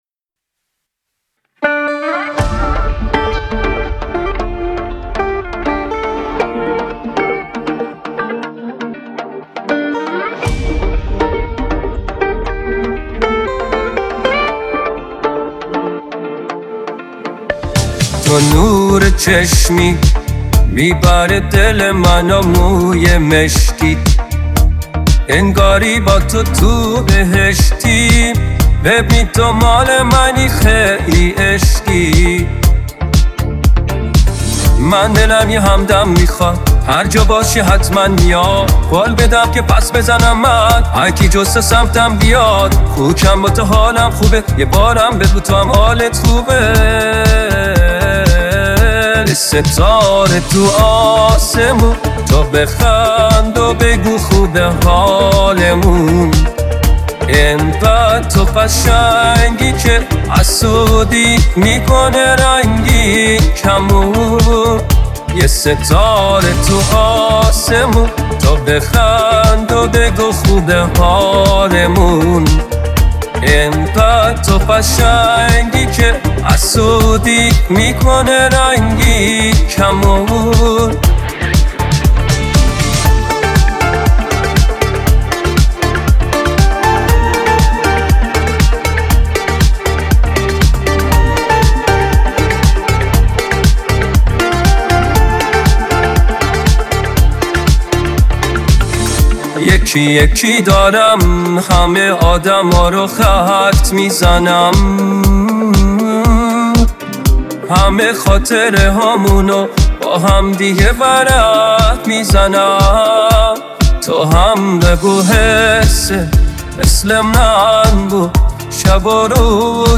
دانلود آهنگ پاپ ایرانی دانلود آهنگ های هوش مصنوعی